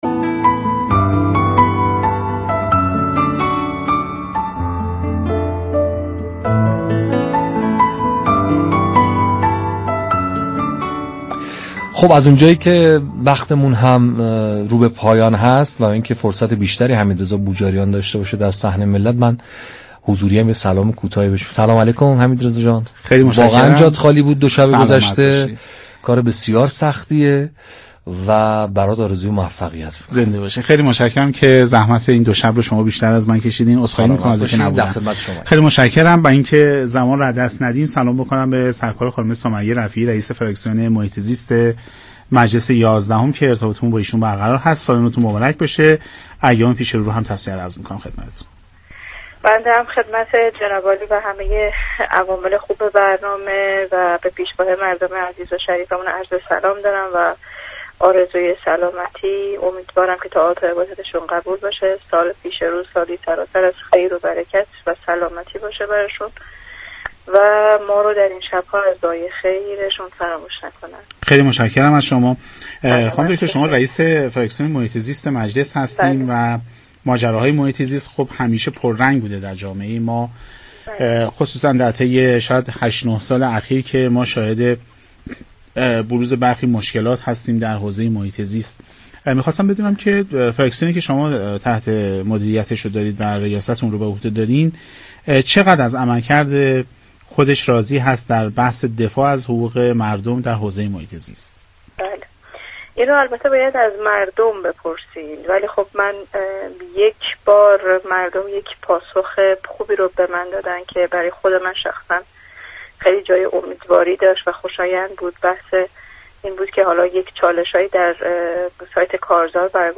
به گزارش پایگاه اطلاع رسانی رادیو تهران، سمیه رفیعی رئیس فراكسیون محیط زیست دور یازدهم مجلس شورای اسلامی در گفت و گو با «جنت آباد» رادیو تهران اظهار داشت: كارهای خیلی زیادی را با همراهی مردم در این فراكسیون دنبال كردیم.